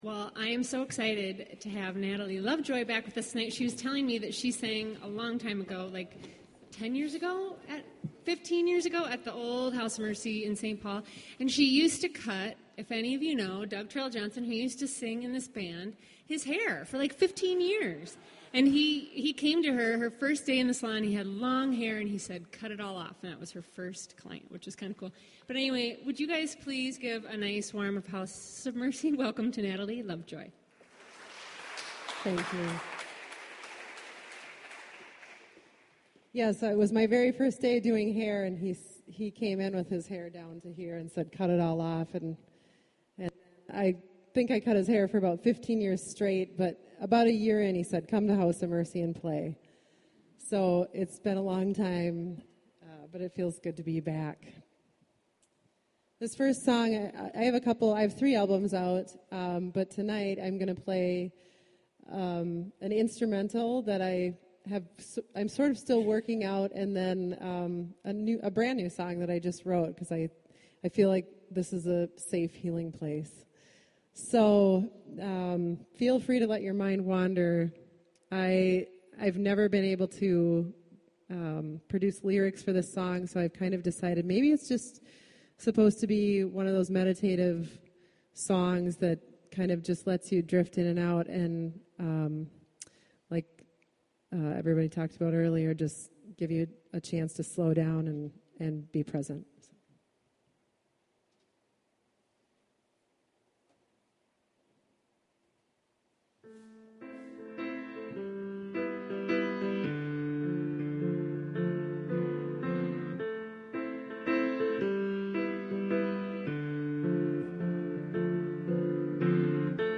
“Instrumental”